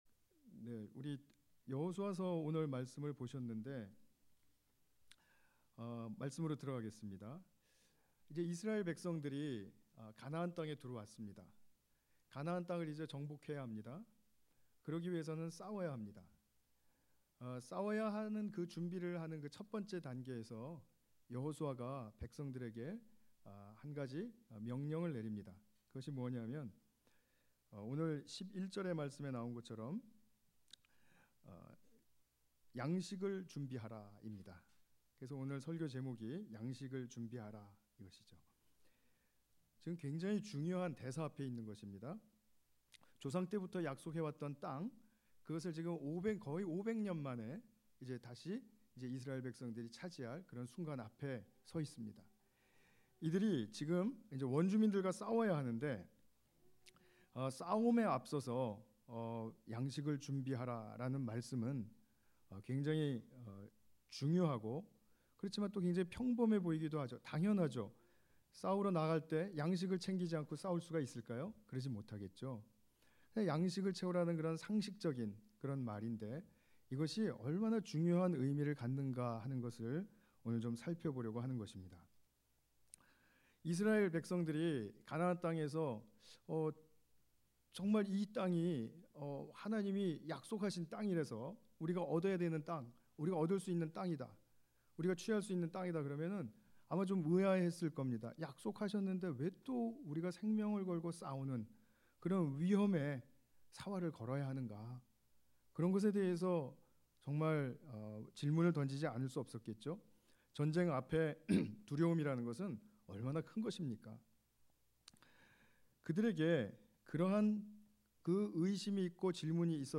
특별예배